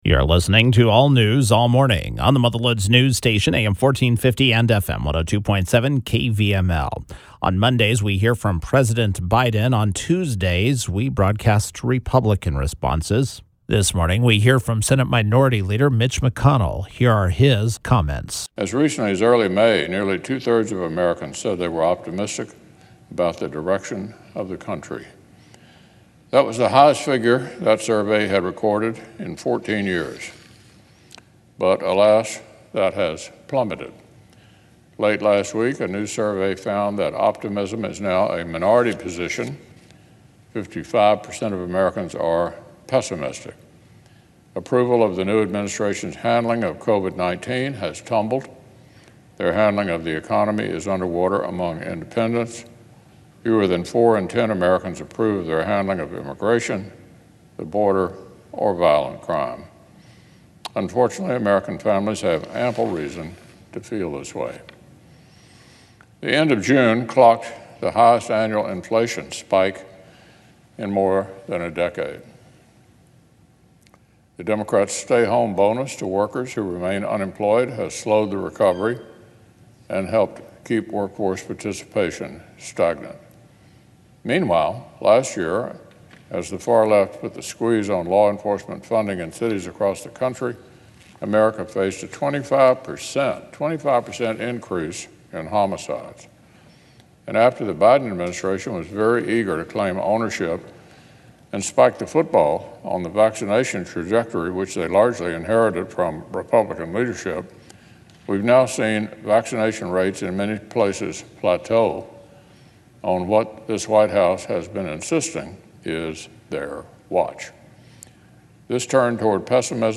On Monday, U.S. Senate Republican Leader Mitch McConnell (R-KY) delivered a speech on the Senate floor, regarding spending.